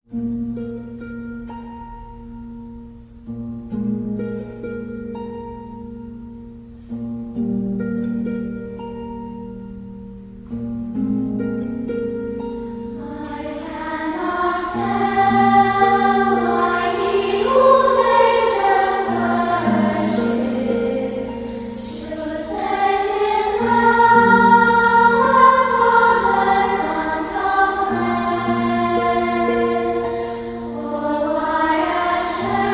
Harp
Keyboard